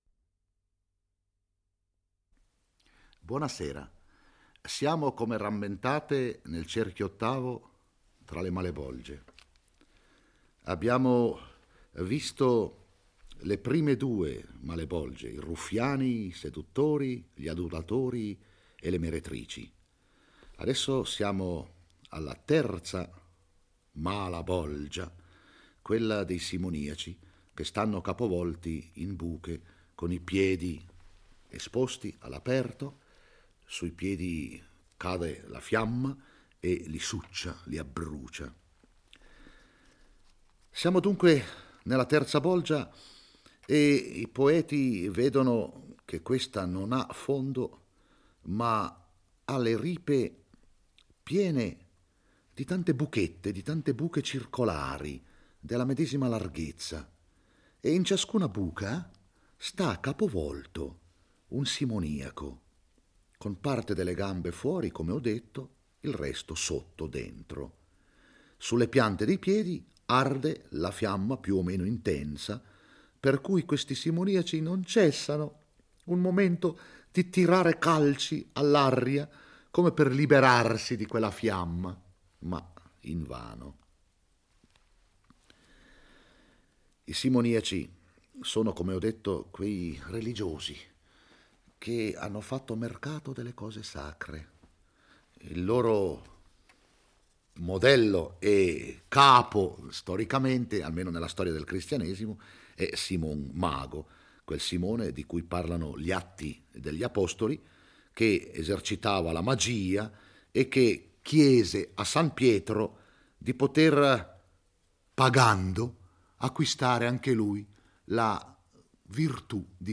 egge e commenta il XIX canto dell'Inferno